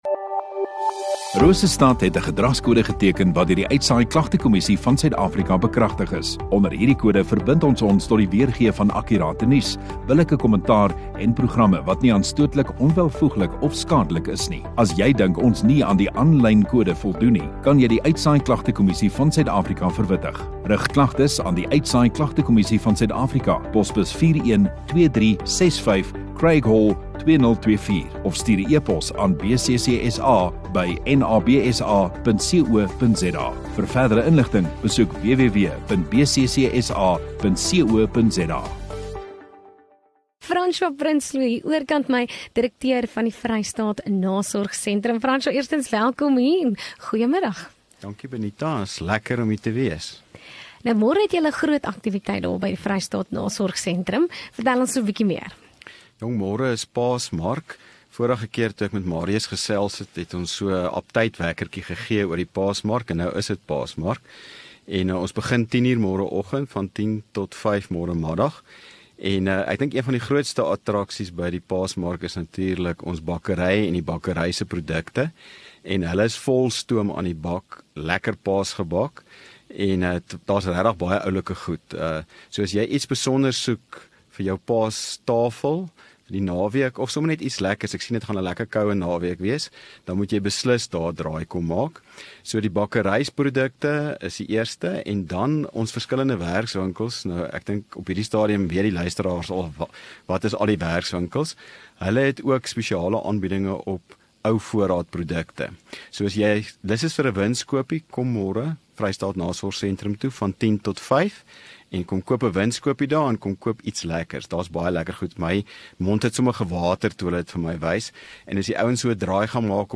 Radio Rosestad View Promo Continue Radio Rosestad Install Gemeenskap Onderhoude 15 Apr VS Nasorg Sentrum